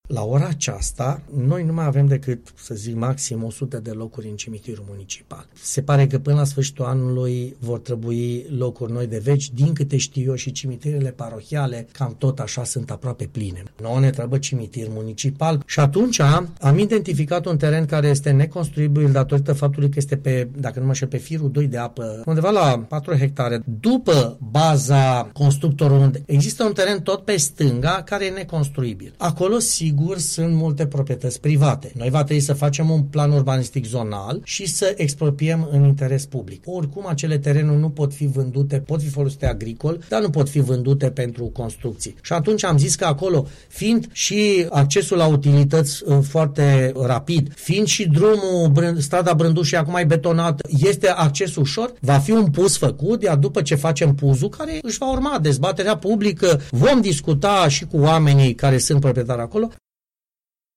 Este afirmația făcuta la Unirea FM de primarul municipiului Alba Iulia, Gabriel Pleșa.